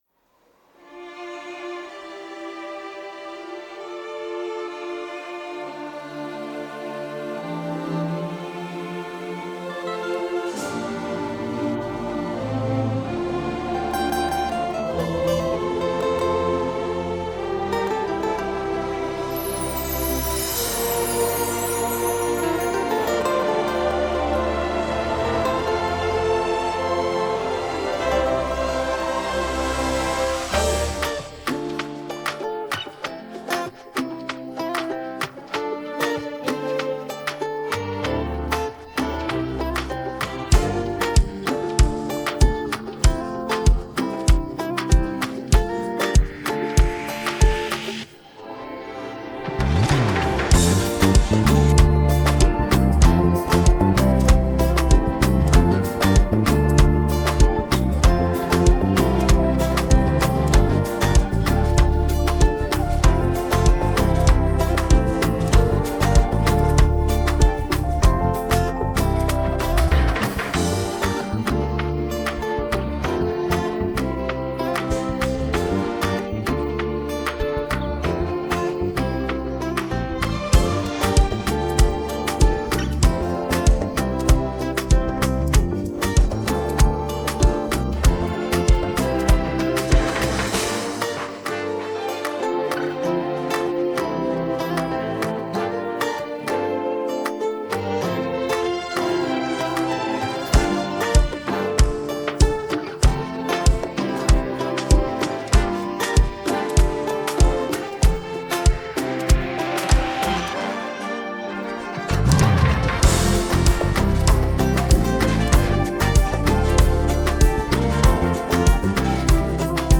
Afro popAfrobeats